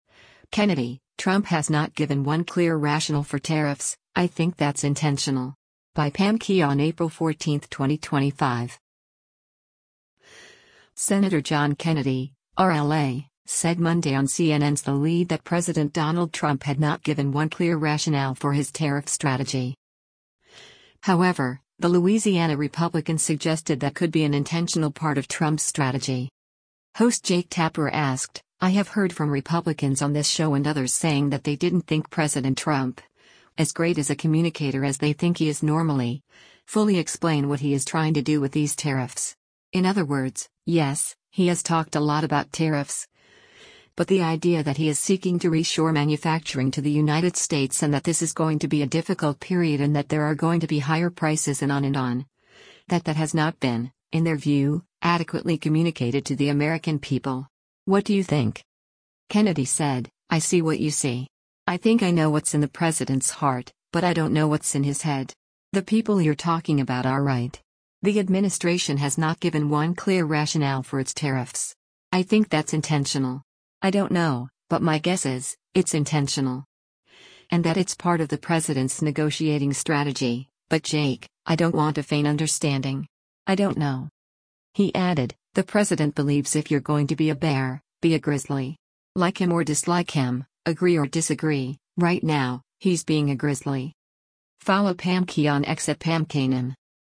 Senator John Kennedy (R-LA) said Monday on CNN’s “The Lead” that President Donald Trump had not “given one clear rationale” for his tariff strategy.